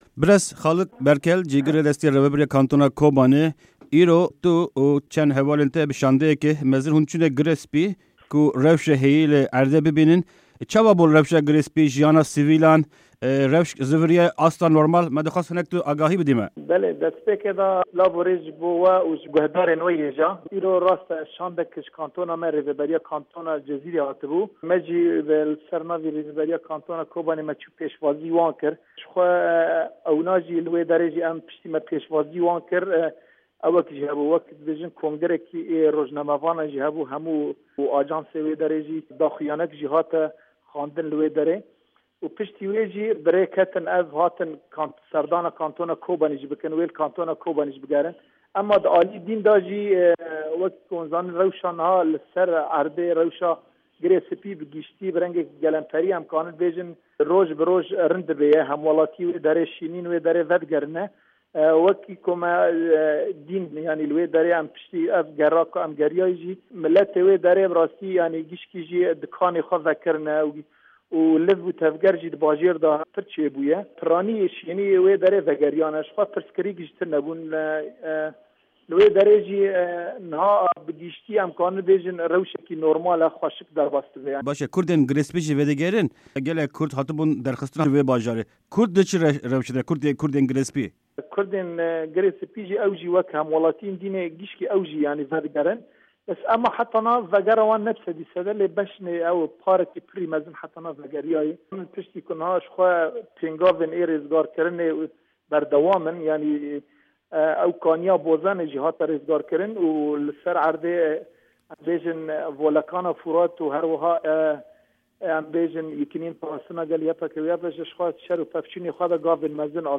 Di hevpeyvîna Dengê Amerîka de Cîgirê Serokê Desteya Rêvebir yê Kantona Kobanê Xalid Berkêl û şendeya Kantona Cizîrê çûn bajarê Girê Spî ku rewşa heyî bibînin.